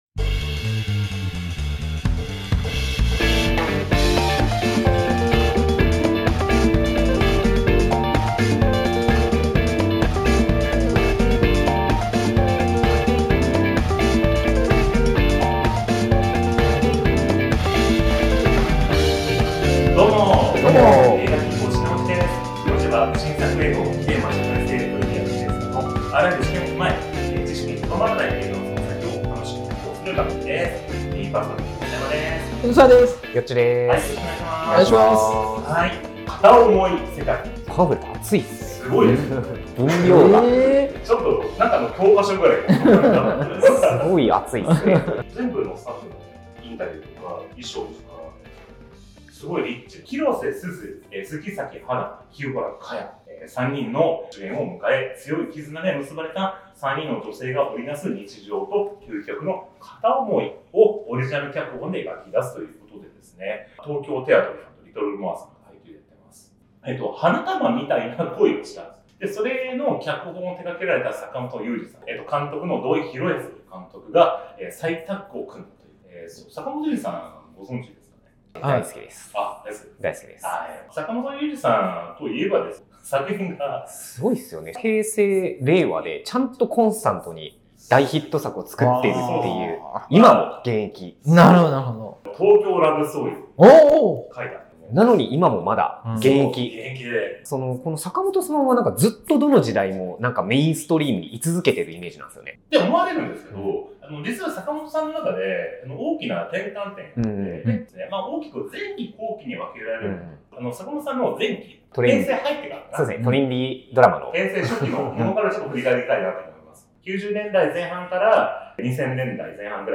30代オジサン初心者3人によるポッドキャストラジオ。仕事、恋愛、サブカルから身近にあった馬鹿話等を和気あいあいと話しています。